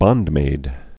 (bŏndmād)